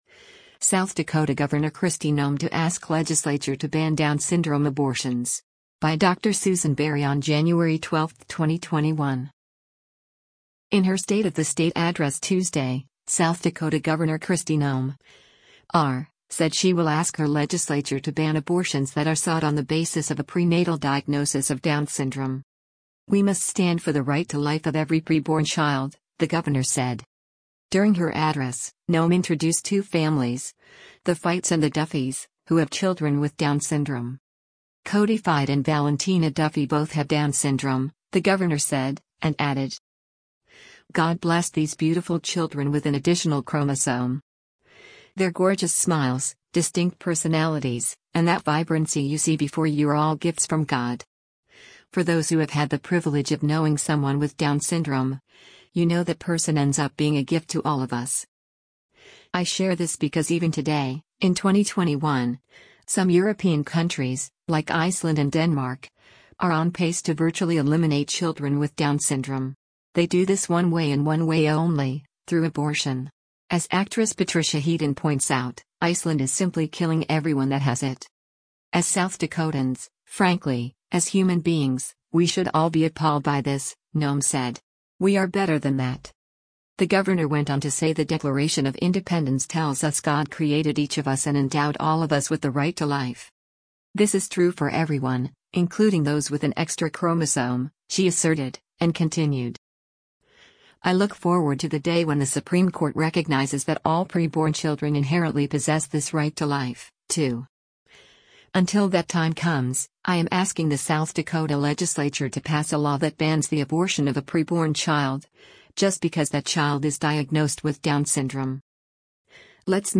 In her State of the State Address Tuesday, South Dakota Gov. Kristi Noem (R) said she will ask her legislature to ban abortions that are sought on the basis of a prenatal diagnosis of Down syndrome.